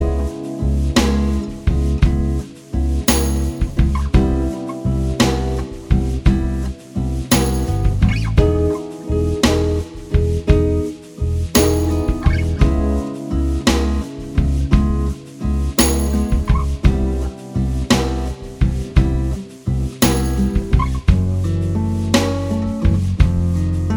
Pop (2000s) 4:23 Buy £1.50